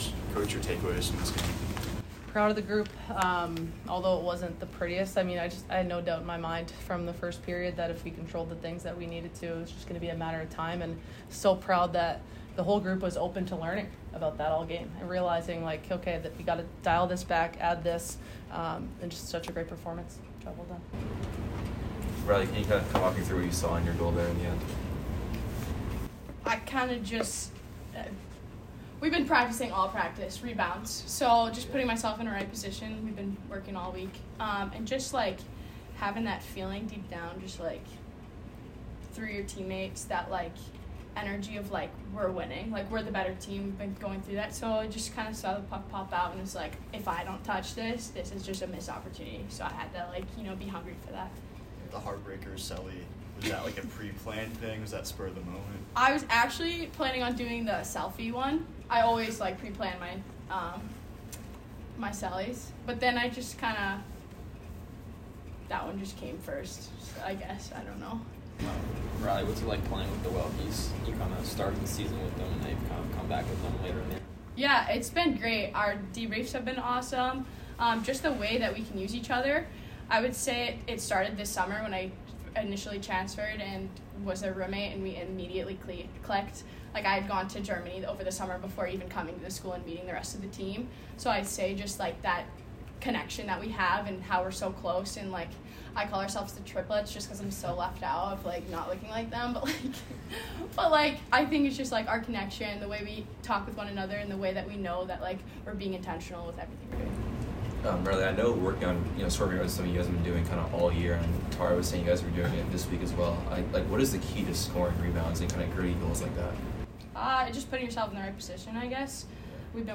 Women's Ice Hockey / Hockey East Quarterfinal Postgame Interview